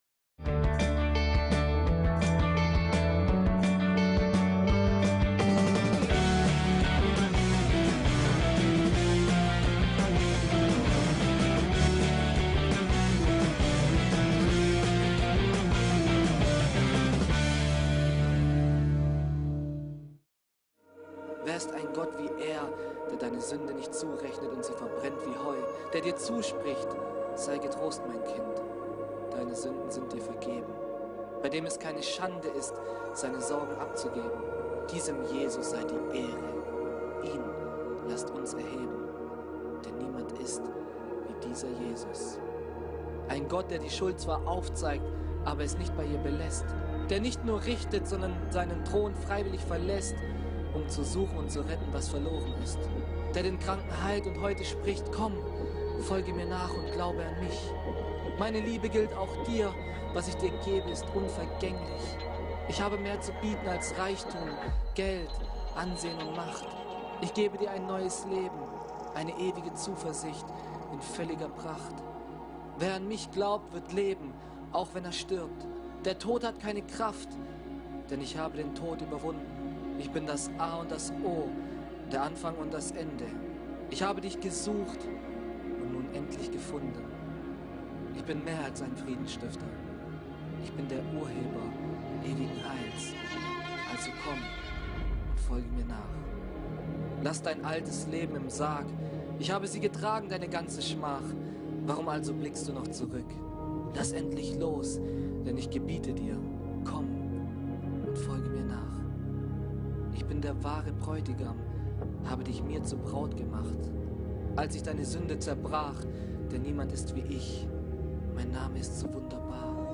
OSTERGOTTESDIENST